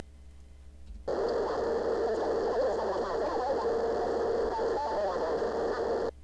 However the sound output is corrupted.
The audio in the file is at a higher pitch than the source.
One file is attached to this post (audio48khz-system.wav. This file is the recording of the playback output from our board. and the second will follow in the next post which is the source file played through the custom board.